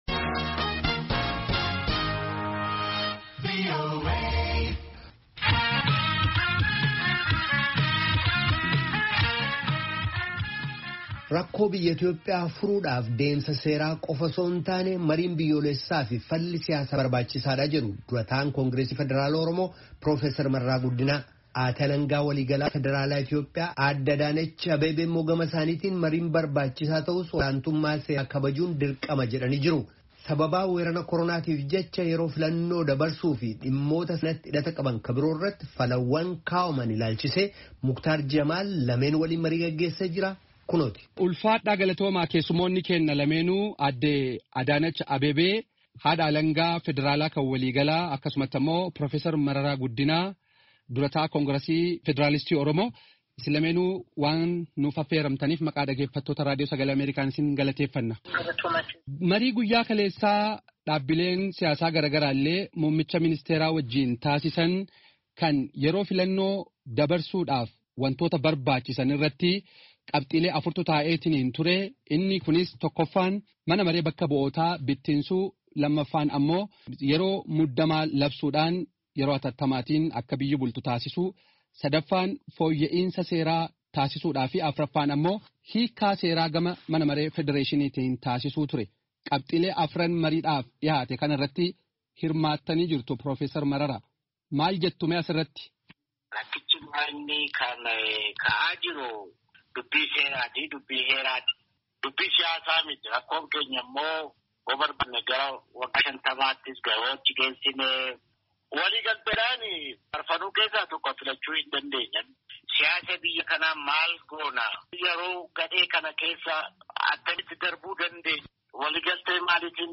Marii Dhimma Yeroo Filannoo Jijjiiruu fi Hojii Mootummaa Itti Fufsiisuu Irratti Geggeessame